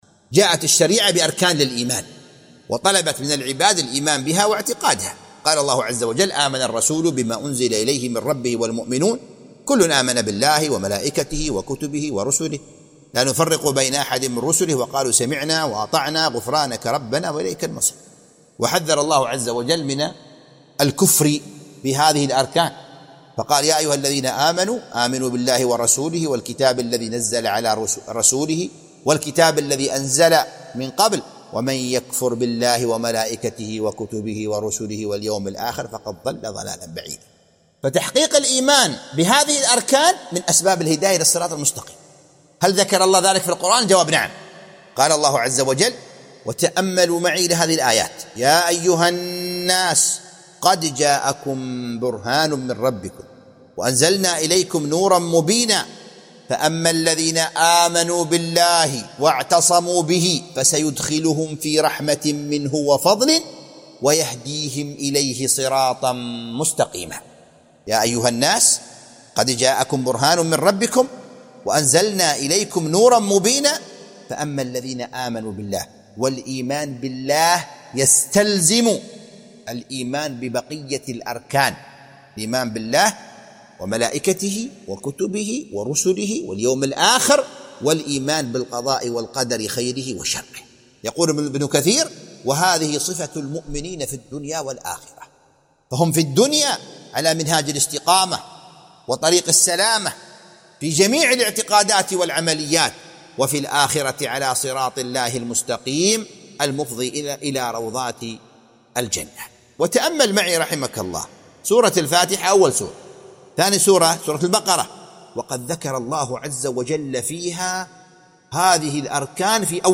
MP3 Mono 44kHz 96Kbps (VBR)